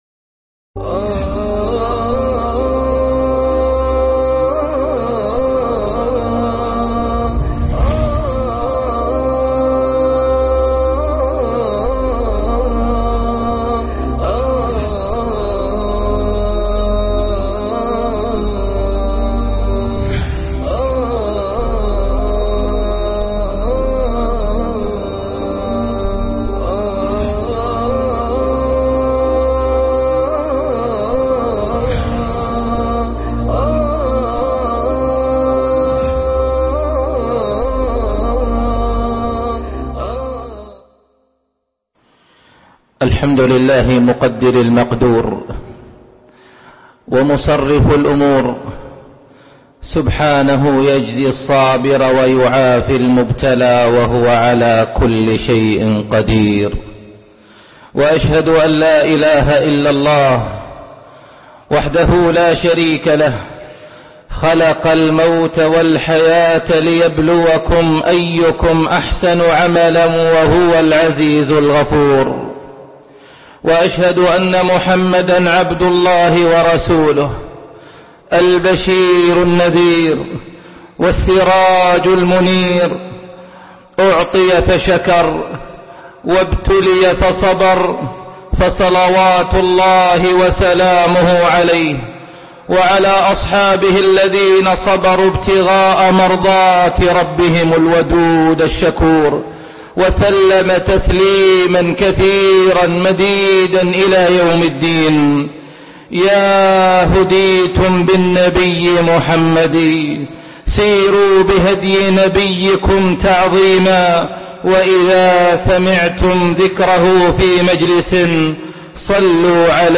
خطب لجمعة